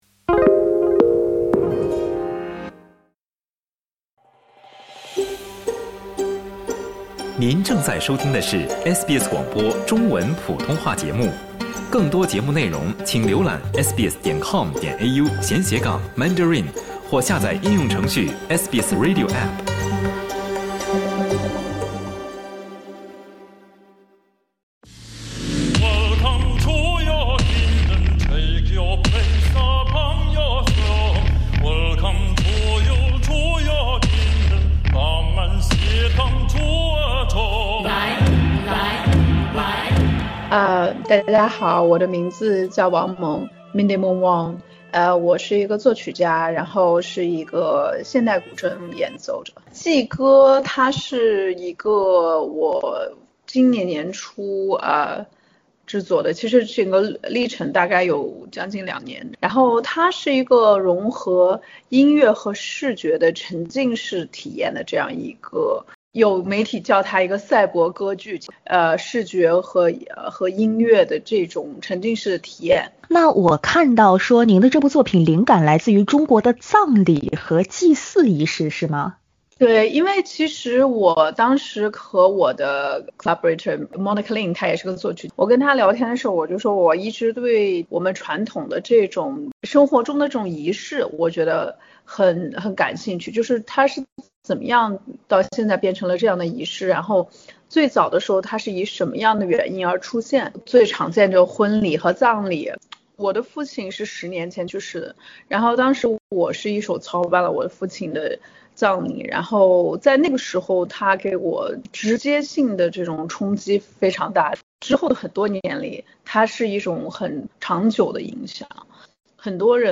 请点击收听详细采访： LISTEN TO 以中式葬礼为灵感 她用音乐疗愈“生与死” SBS Chinese 08:25 cmn 欢迎下载应用程序SBS Audio，订阅Mandarin。